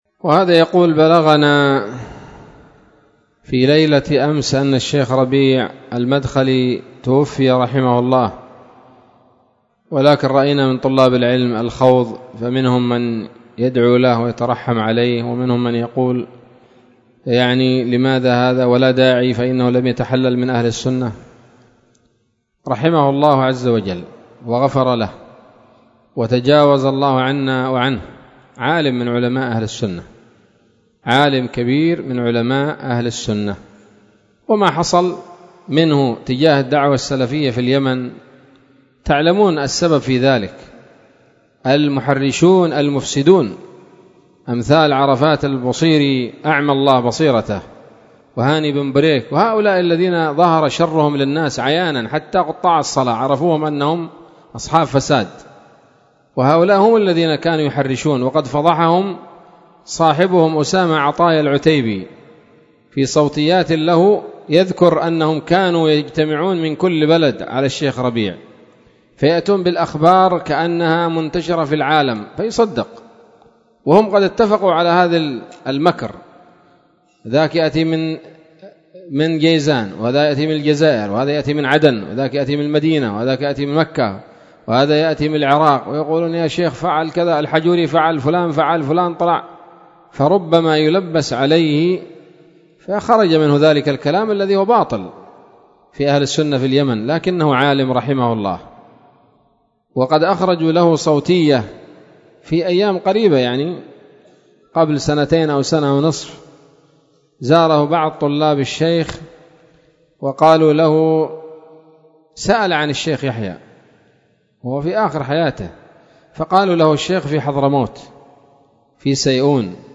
إجابة عن سؤال بعنوان: (( بلغنا ليلة أمس أن الشيخ ربيع المدخلي توفي - رحمه الله - )) ليلة الجمعة 16 شهر محرم 1447هـ، بدار الحديث السلفية بصلاح الدين